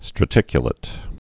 (stră-tĭkyə-lĭt)